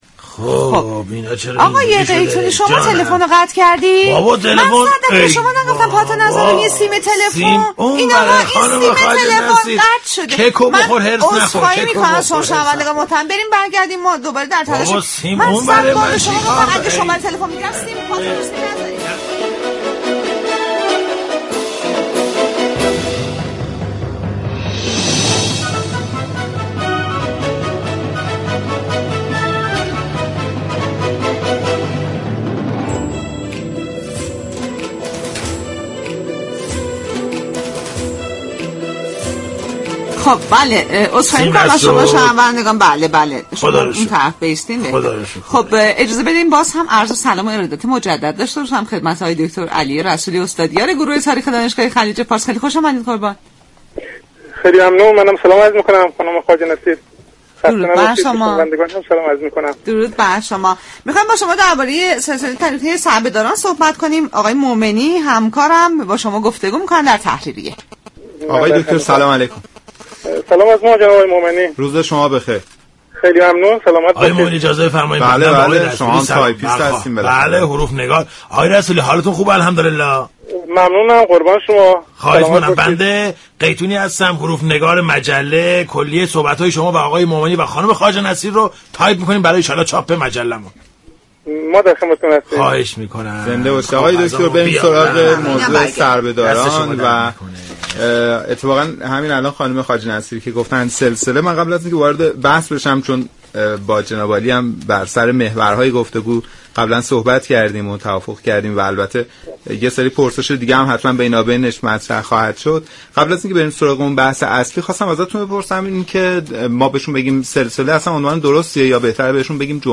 گفتگو